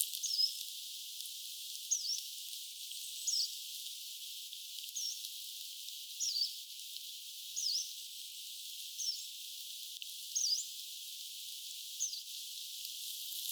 taigauunilintu ääntelee
taigauunilintu_aantelee.mp3